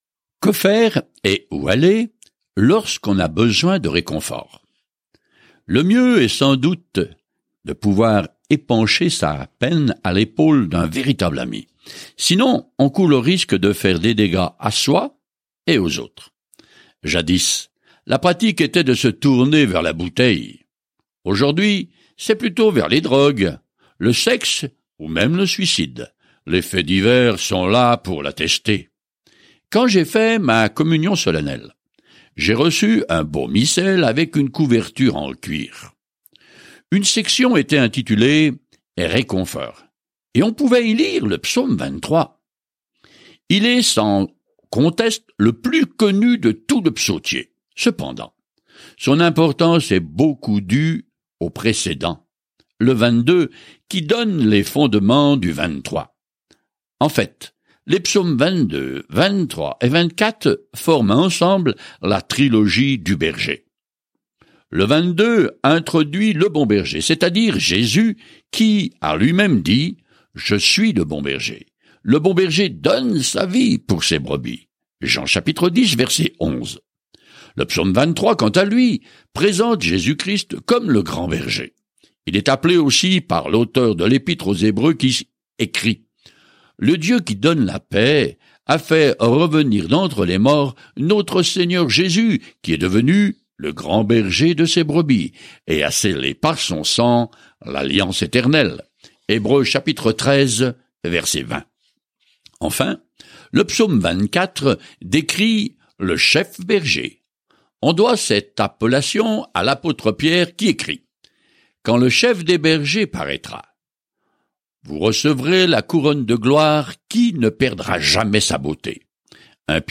Écritures Psaumes 23 Psaumes 24 Jour 14 Commencer ce plan Jour 16 À propos de ce plan Les Psaumes nous donnent les pensées et les sentiments d'une gamme d'expériences avec Dieu ; probablement chacun d’eux initialement mis en musique. Parcourez quotidiennement les Psaumes en écoutant l’étude audio et en lisant des versets sélectionnés de la parole de Dieu.